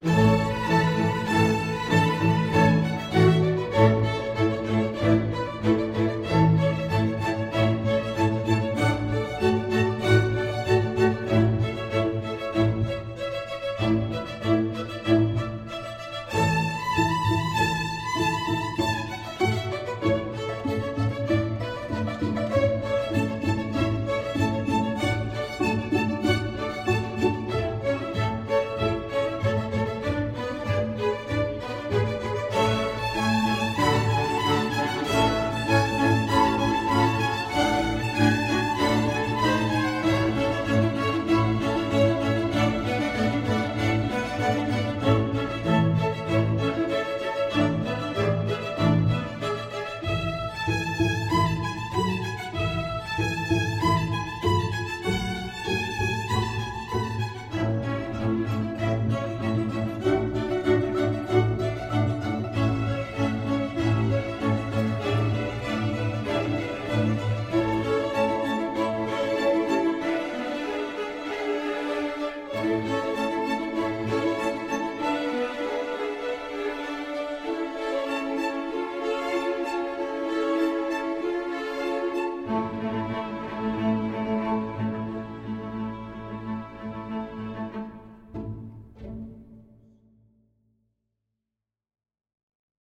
Voicing: String Orchestra C